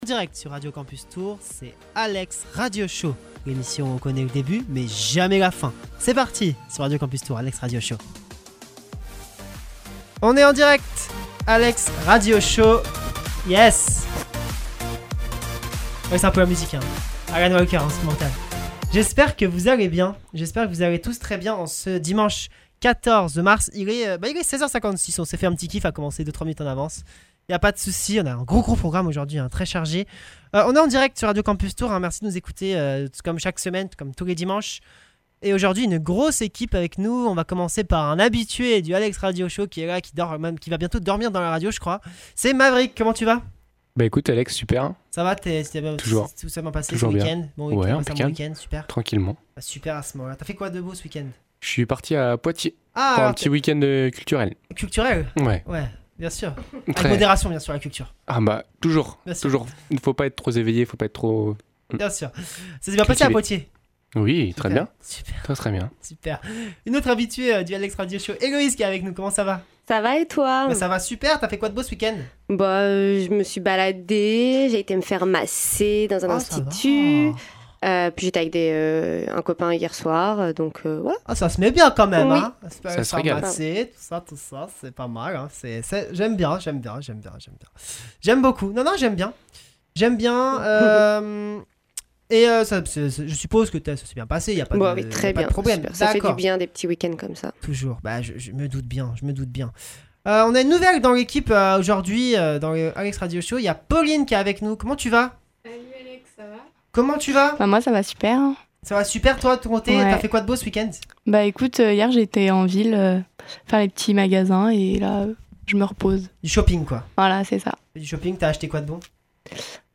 Nous parlerons ensuite des vacances de l’équipe : anecdotes, moments insolites etc. Nous terminerons l’émission avec un jeu bien drôle !! Des musiques viendront rythmer le show toutes les 8 à 10 minutes et vous retrouverez la […]